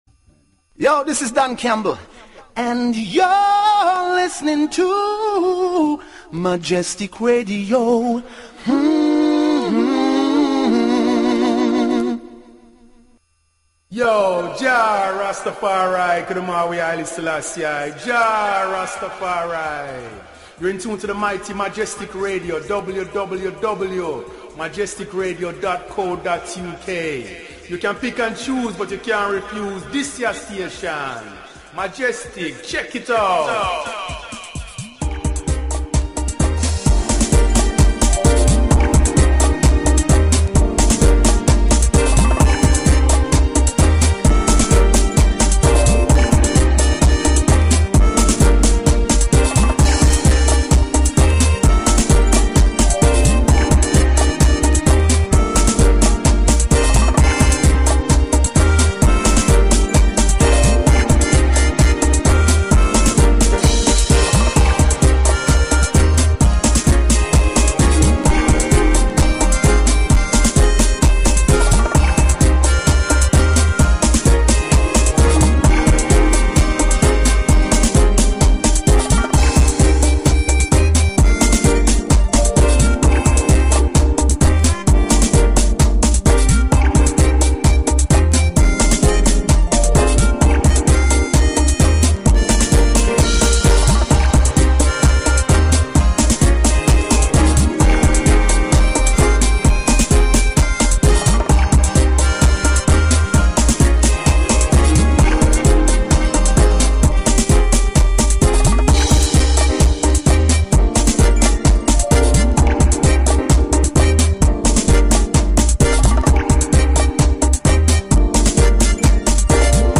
An occasional Interview Show with Special Guests